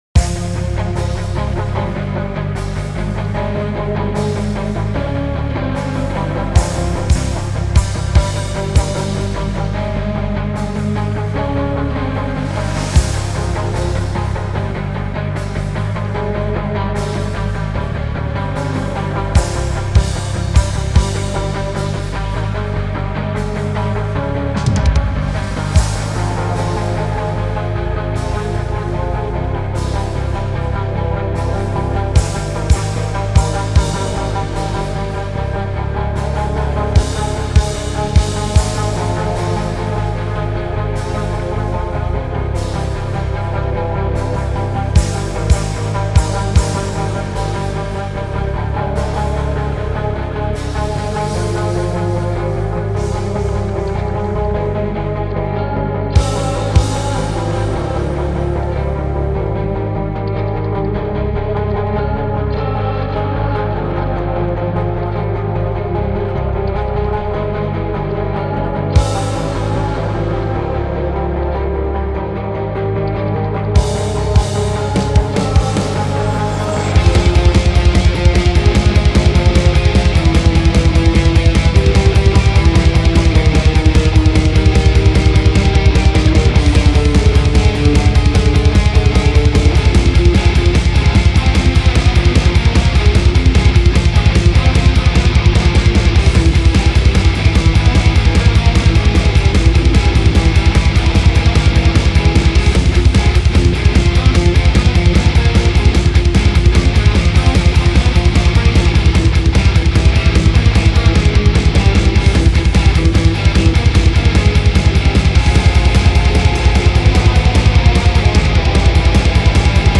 Пока что я сделал такие бочки и гитары заменил, добавил бас.